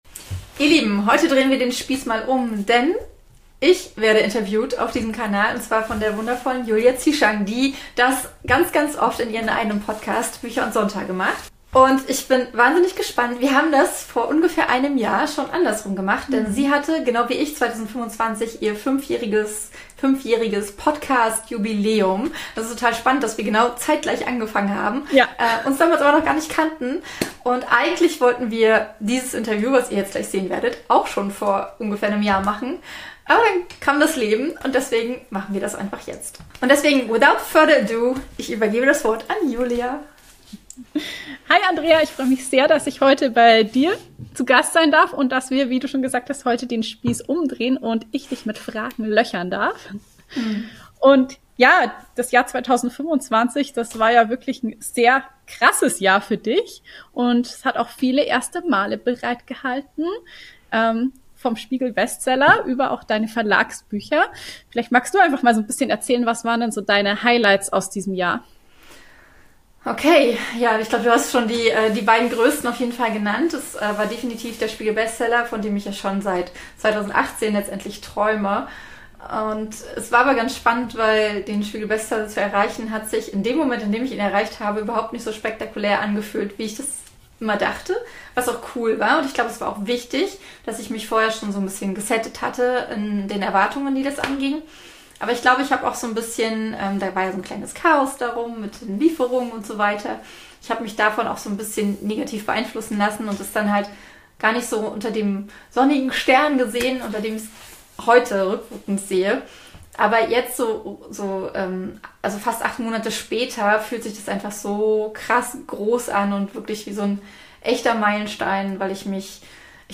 interviewt